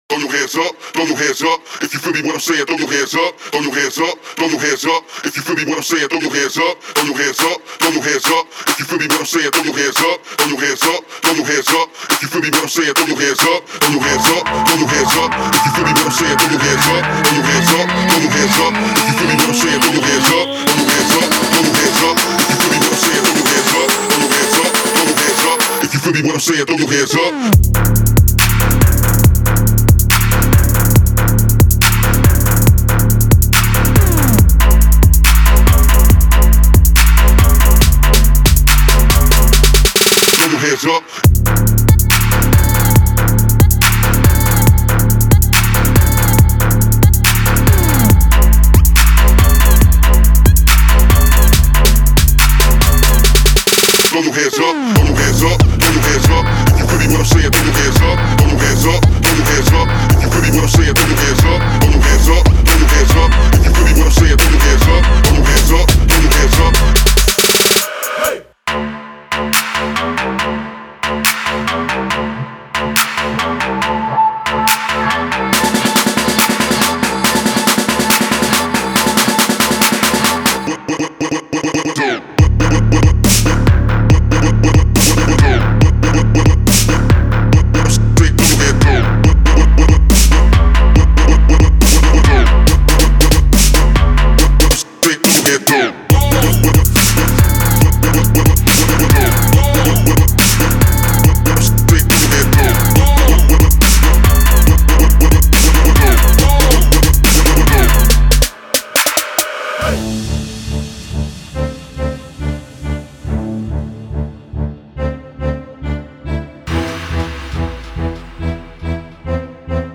Genre: Soundtrack.